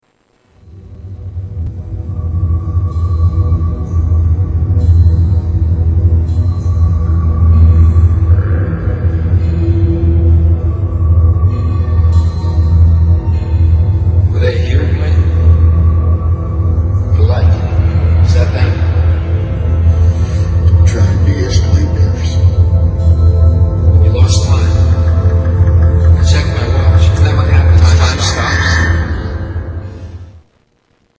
Television Music